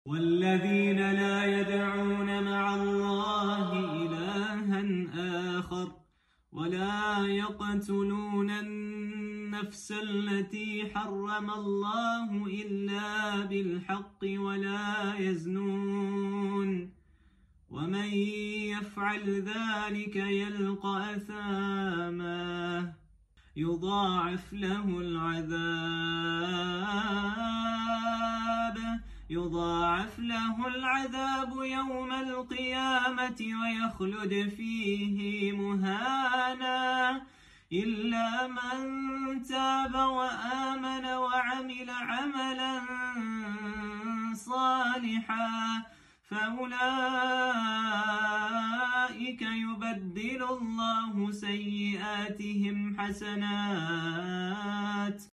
Merged both Surah Furqan Recitation sound effects free download
Merged both Surah Furqan Recitation styles.